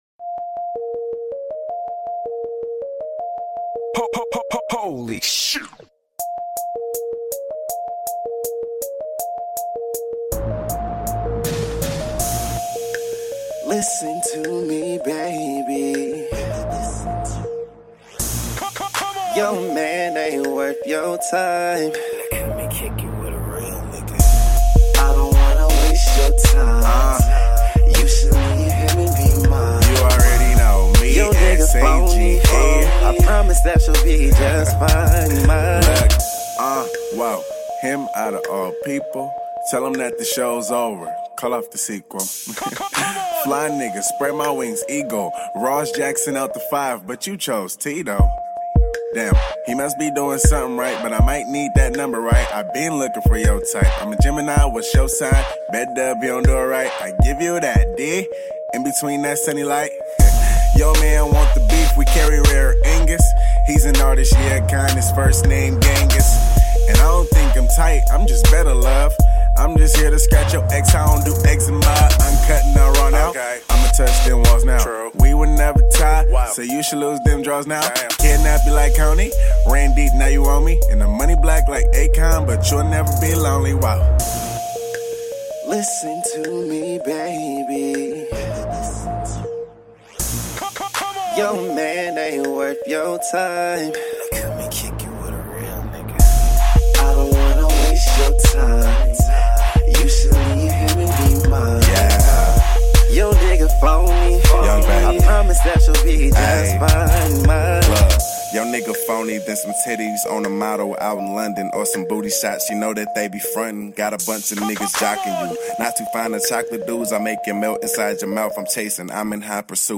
HIP HOP.